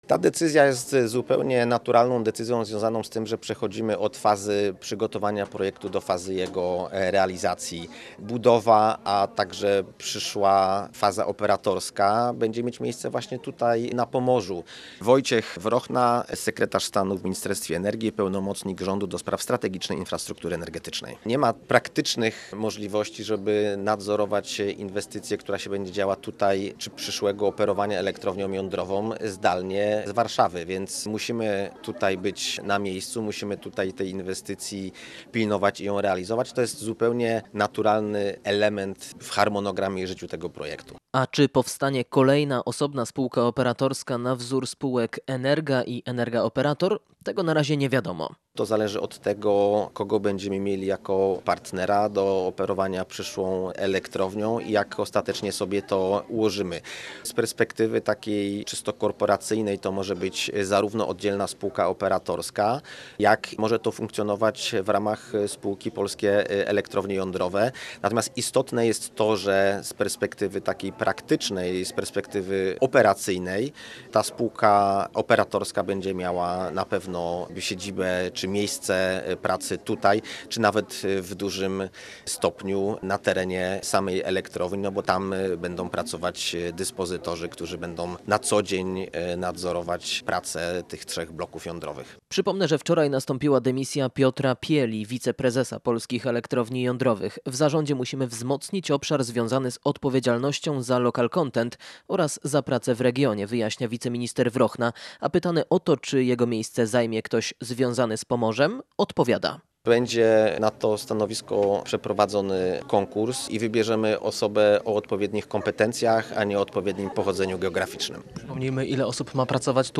– To ułatwienie logistyki, bo wkrótce serce polskiej energetyki będzie biło na Pomorzu – mówi Wojciech Wrochna, wiceminister energii.